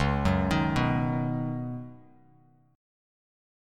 C#9sus4 chord